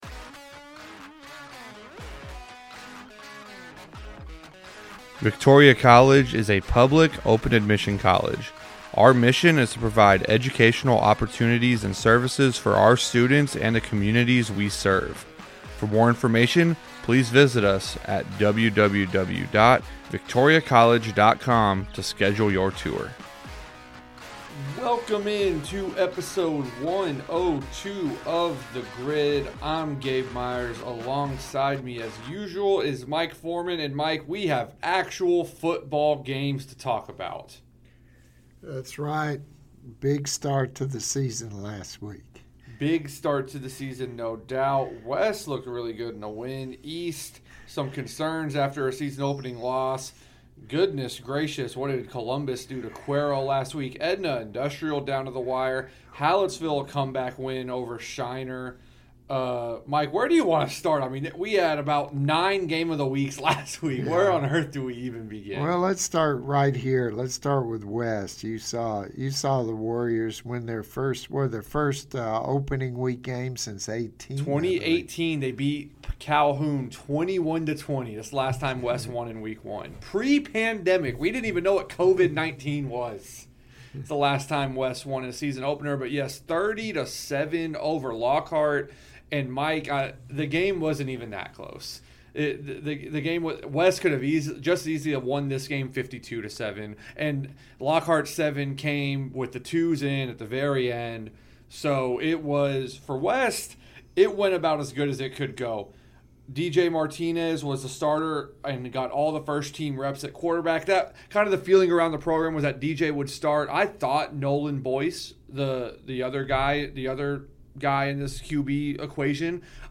The Victoria Advocate's sports team discuss prep and college sports in the Crossroads and South Texas.